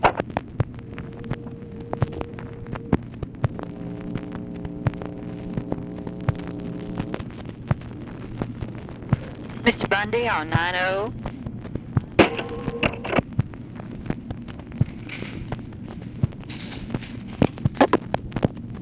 Conversation with OFFICE SECRETARY
Secret White House Tapes